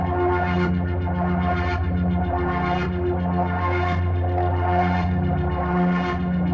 Index of /musicradar/dystopian-drone-samples/Tempo Loops/110bpm
DD_TempoDroneE_110-F.wav